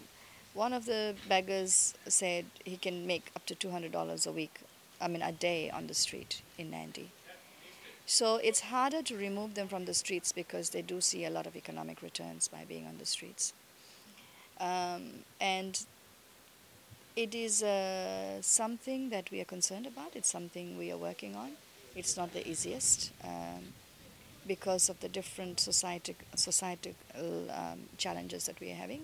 Assistant Minister for Women, Sashi Kiran